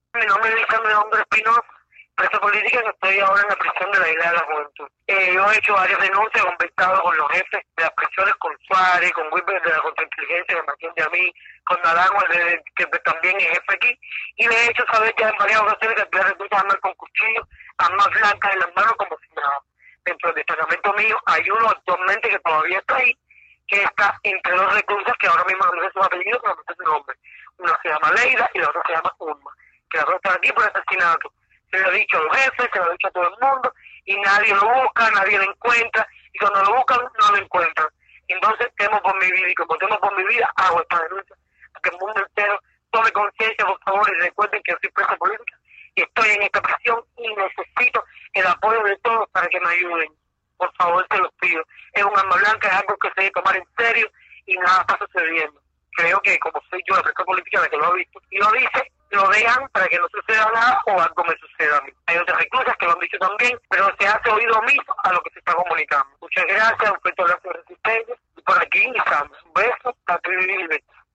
Así lo denunció en un audio enviado por familiares a Martí Noticias.